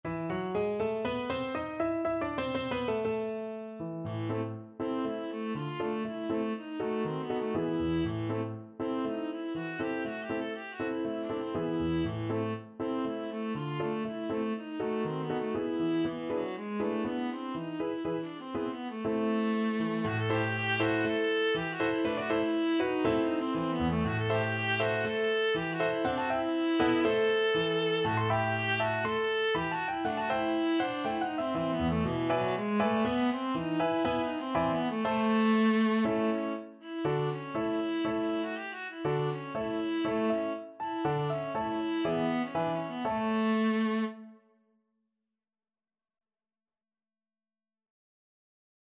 Viola
2/4 (View more 2/4 Music)
E minor (Sounding Pitch) (View more E minor Music for Viola )
Allegro (View more music marked Allegro)